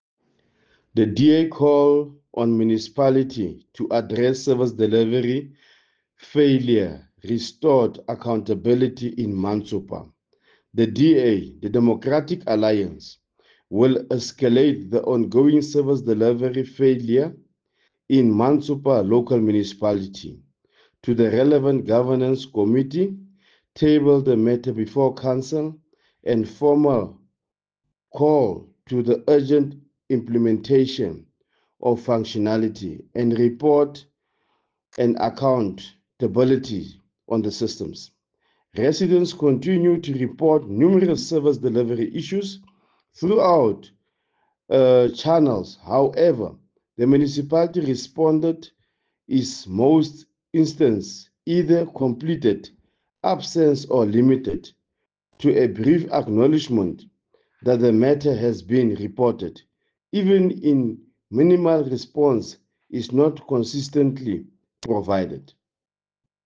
Sesotho soundbites by Cllr Nicky van Wyk.